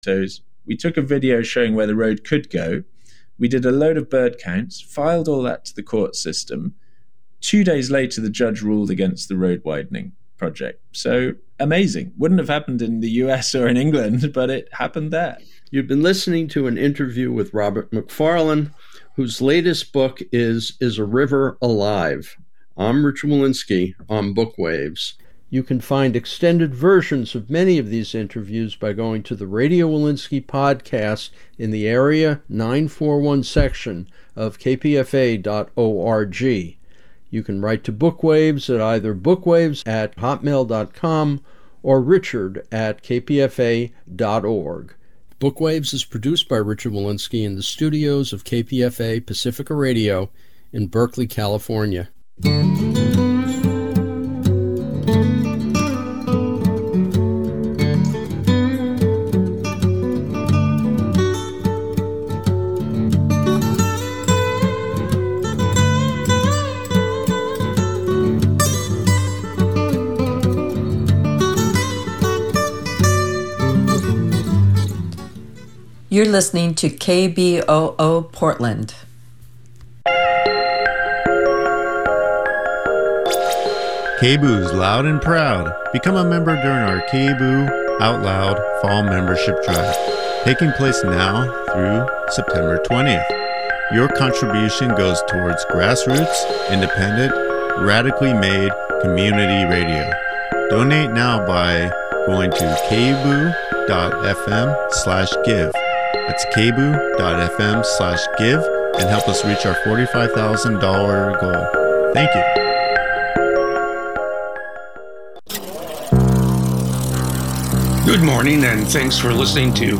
A panel broadcast reviewing the latest films and shows from the multiplex to the arthouse and beyond.